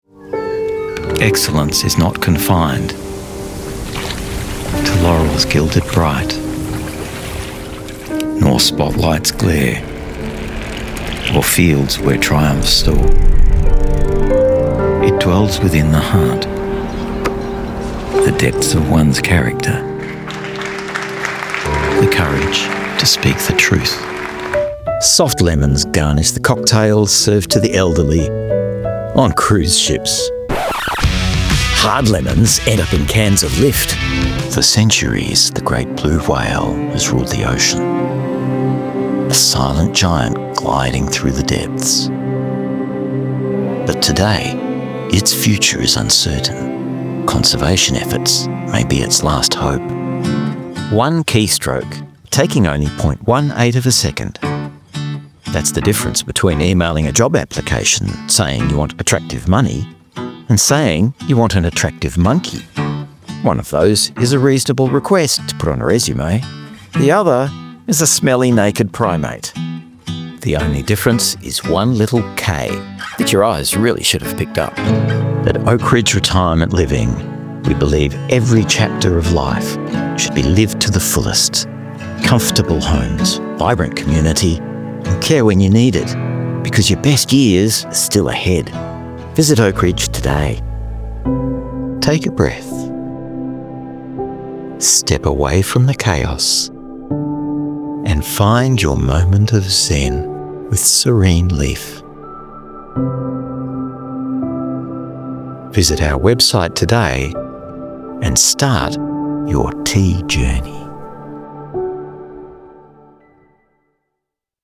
Older Sound (50+)